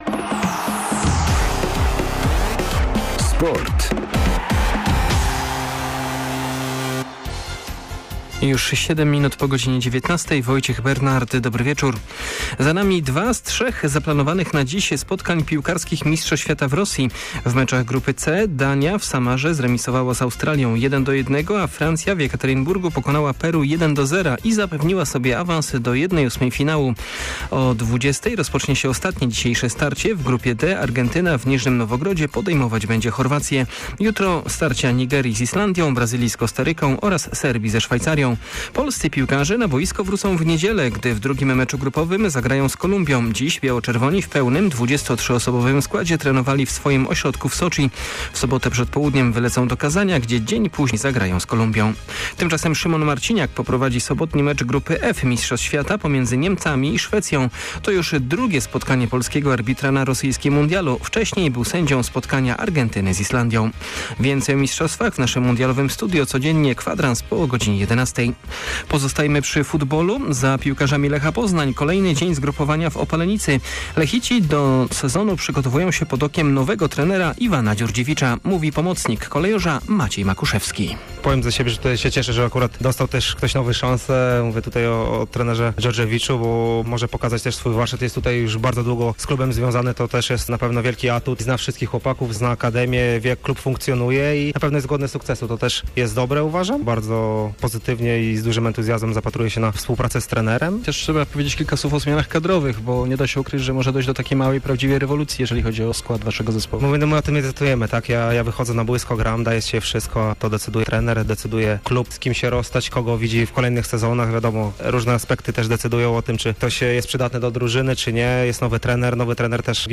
21.06 serwis sportowy godz. 19:05